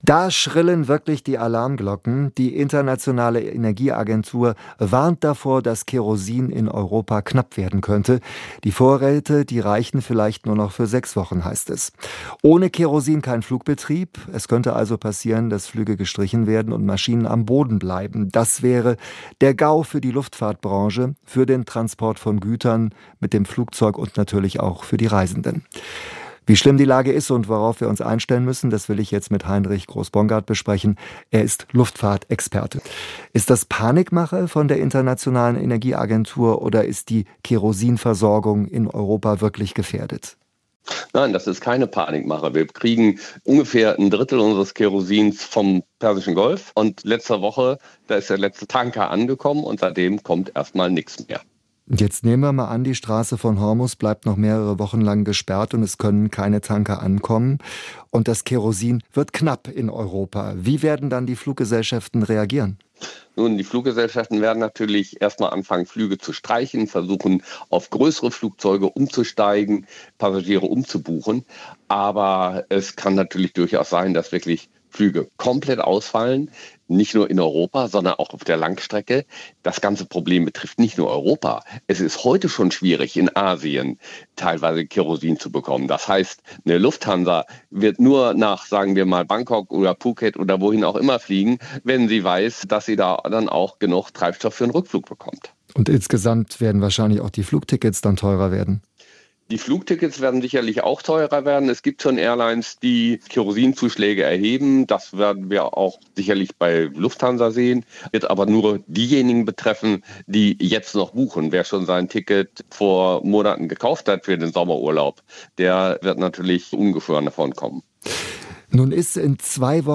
Die besten Interviews aus dem Radioprogramm SWR Aktuell: jederzeit zum Nachhören und als Podcast im Abo